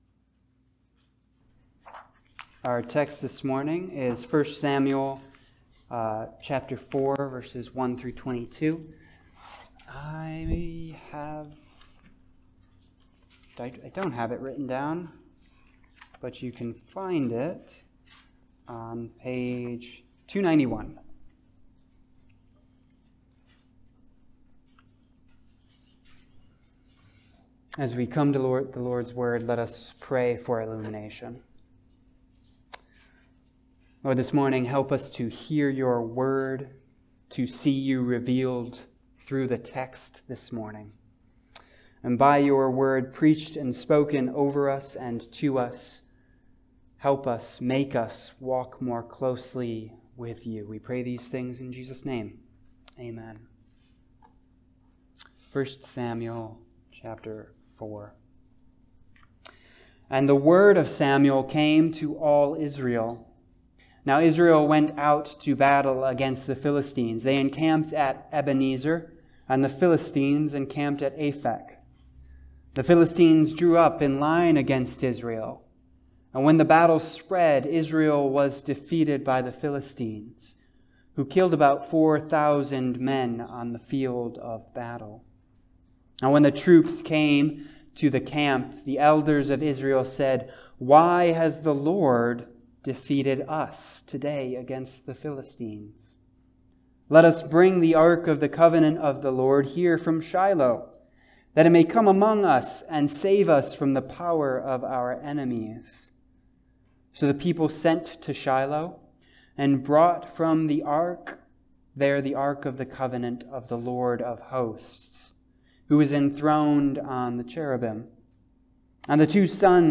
Passage: 1 Samuel 4 Service Type: Sunday Service